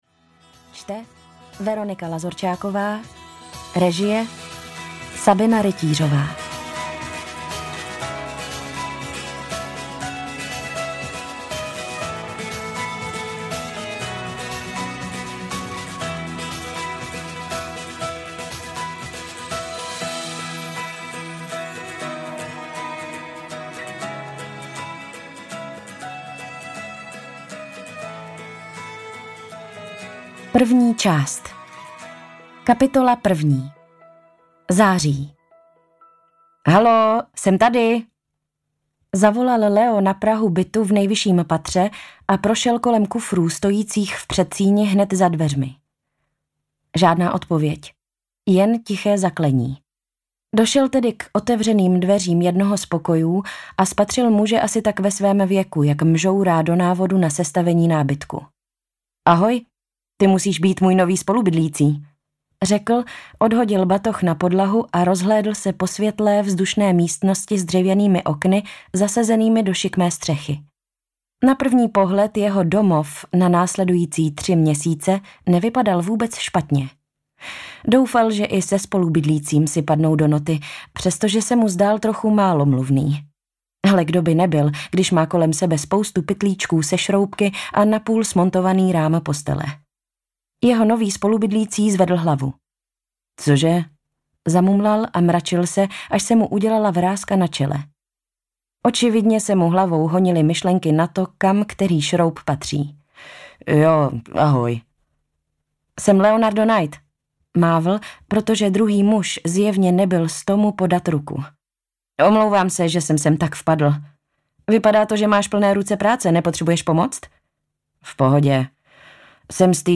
Hospůdka v Praze audiokniha
Ukázka z knihy